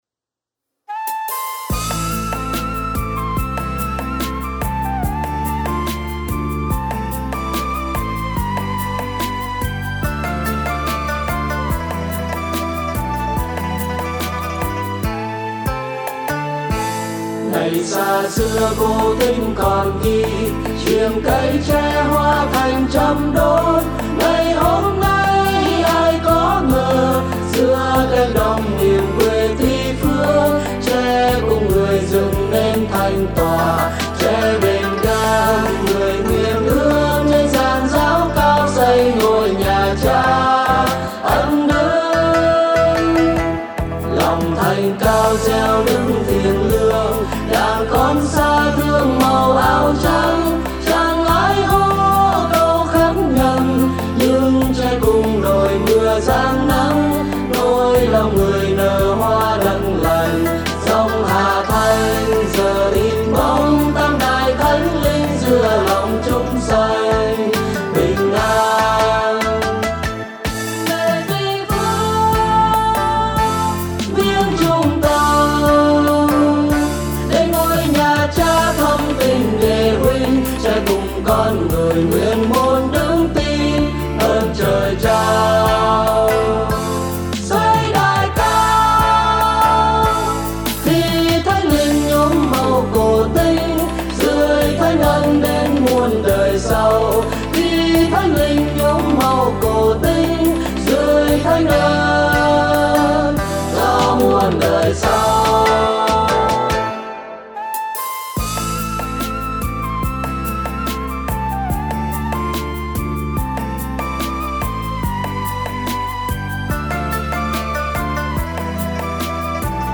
•   Vocal  02.